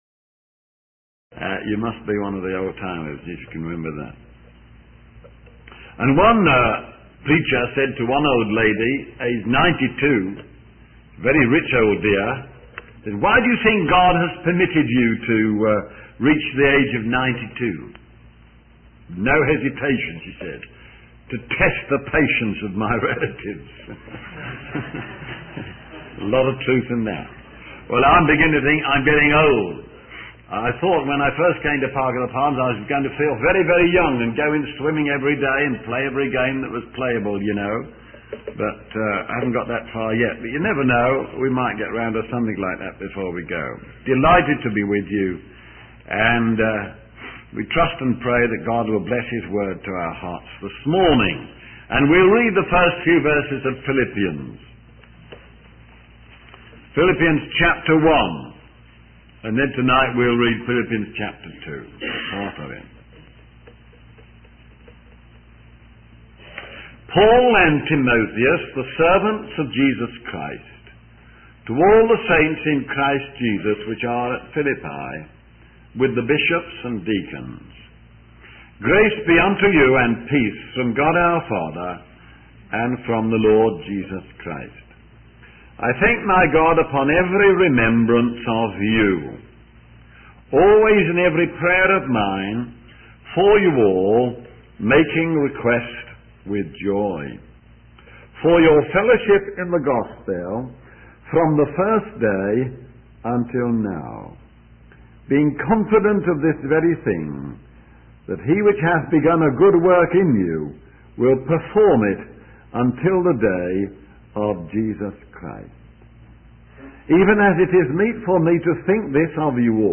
In this sermon, the speaker shares his experience of setting examinations for his students at Moreland.